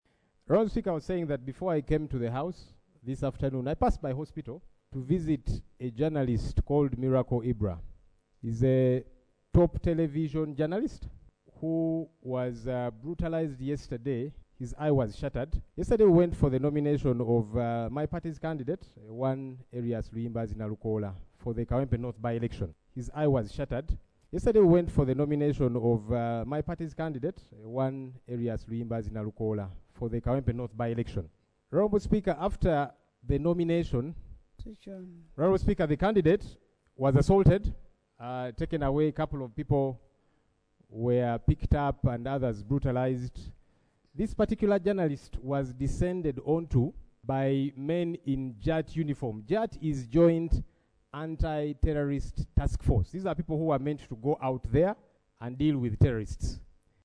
Leader of the Opposition Hon. Joel Ssenyonyi speaking during the plenary sitting on Thursday 27 February 2025
AUDIO: LOP Joel Ssenyonyi